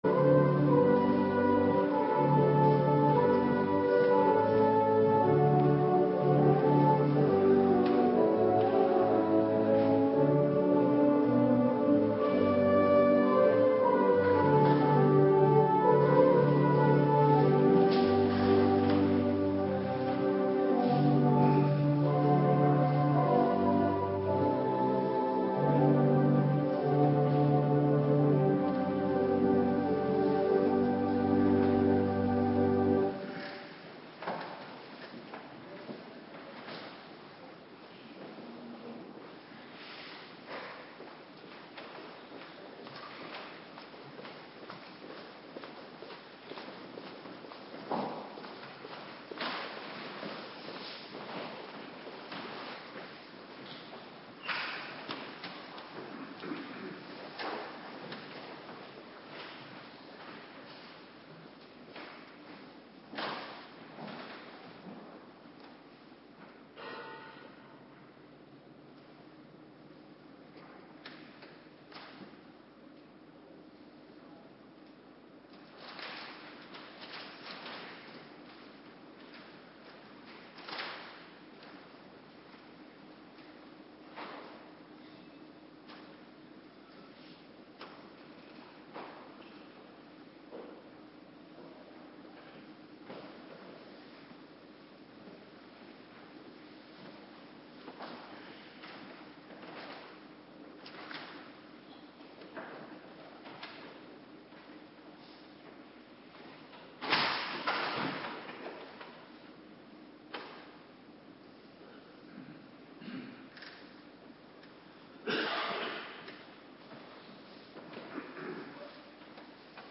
Woensdagavonddienst - Alle clusters
Locatie: Hervormde Gemeente Waarder
Woensdagavond Bijbellezing